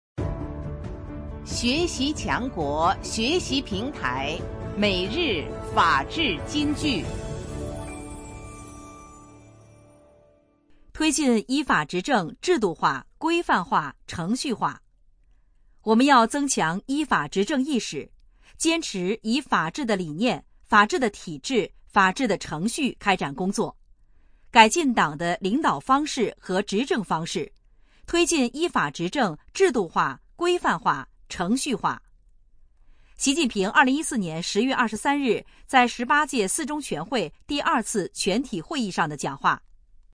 每日法治金句（朗读版）|推进依法执政制度化、规范化、程序化 _ 学习宣传 _ 福建省民政厅